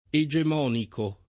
egemonico [ e J em 0 niko ]